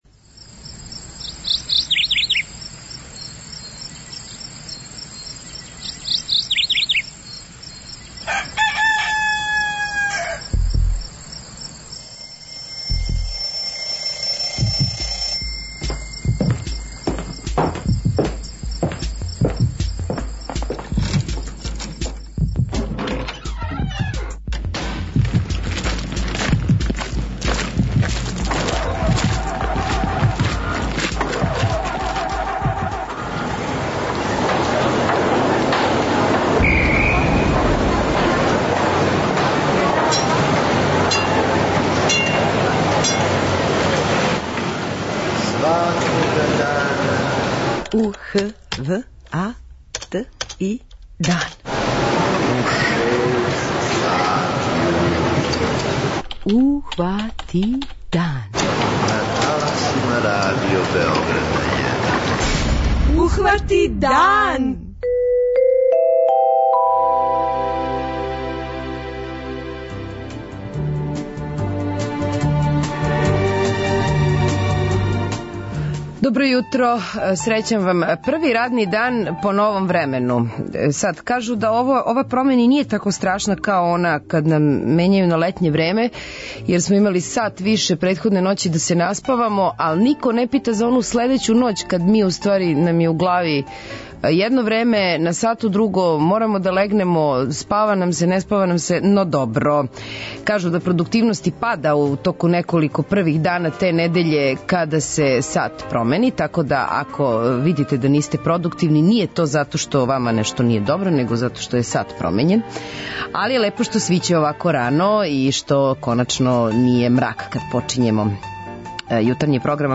преузми : 43.16 MB Ухвати дан Autor: Група аутора Јутарњи програм Радио Београда 1!